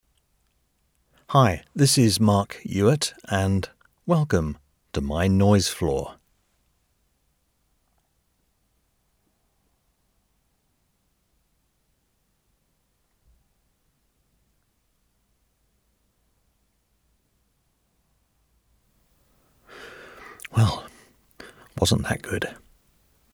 Older Sound (50+)
Warm, articulate British voice with natural authority and clean, confident delivery.
Studio Quality Sample
Noise Floor With Intro